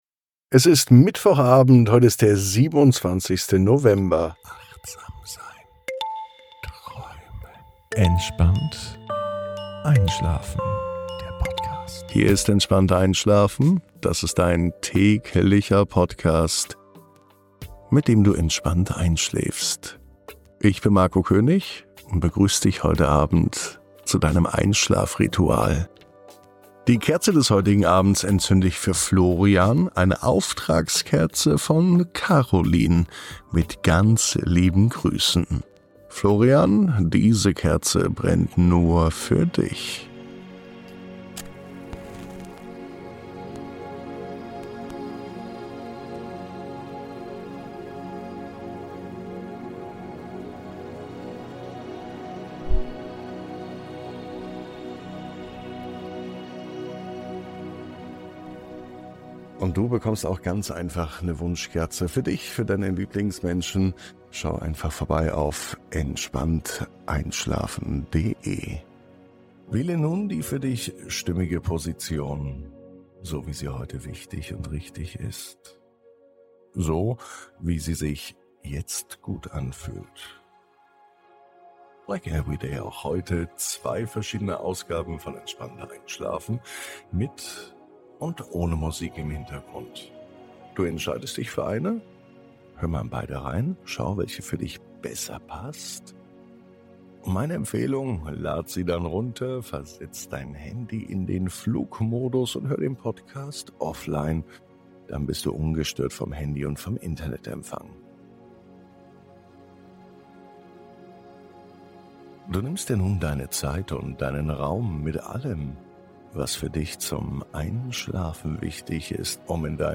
Diese Folge bietet dir zwei Versionen, mit und ohne Musik, um dich entspannt ins Land der Träume zu begleiten.